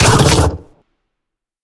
biter-roar-big-2.ogg